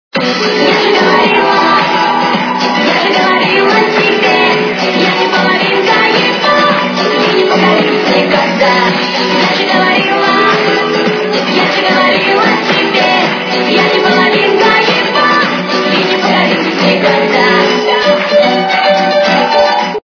русская эстрада
качество понижено и присутствуют гудки